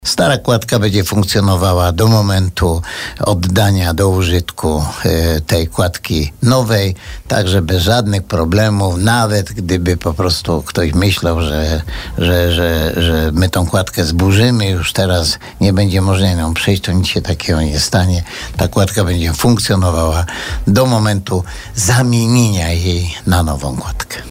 W kwestii terminu jej powstania gospodarz Żywca wypowiadał się dzisiaj na antenie Radia Bielsko.